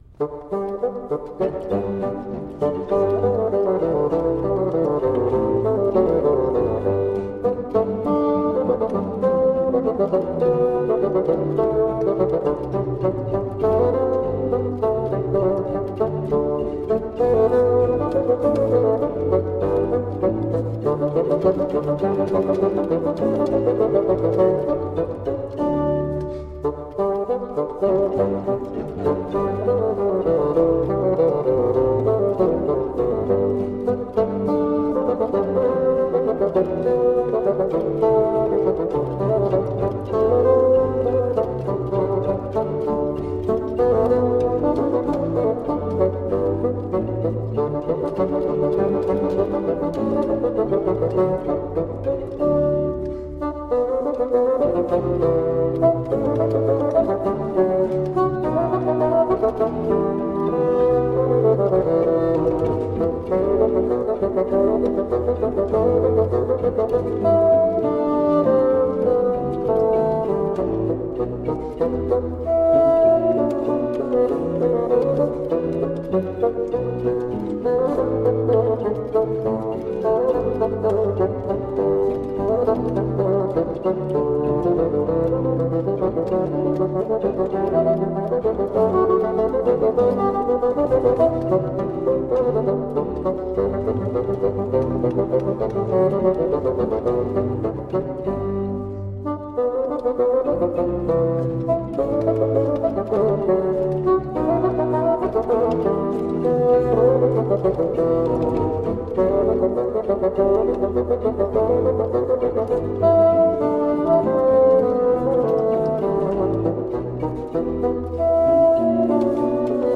Classical, Chamber Music, Baroque, Instrumental, Bassoon
Harpsichord, Organ